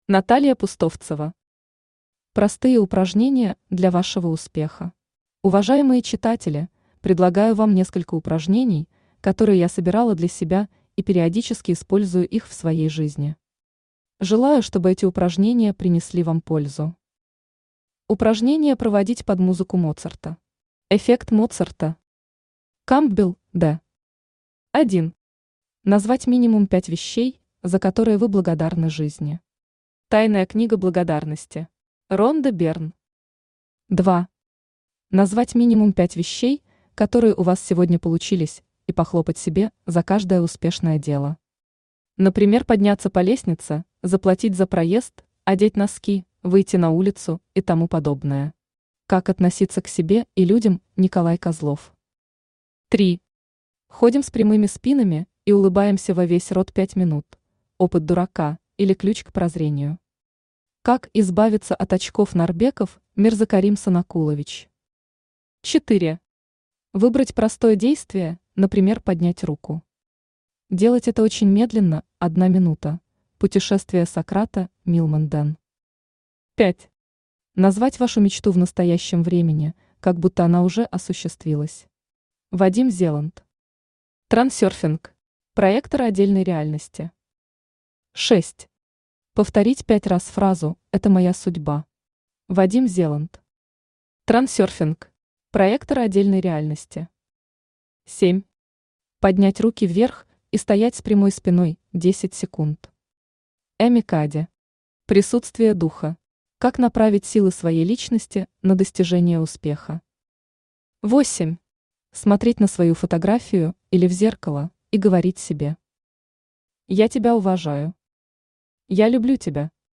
Аудиокнига Тренинг успеха | Библиотека аудиокниг
Aудиокнига Тренинг успеха Автор Наталья Сергеевна Пустовцева Читает аудиокнигу Авточтец ЛитРес.